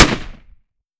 7Mag Bolt Action Rifle - Gunshot A 001.wav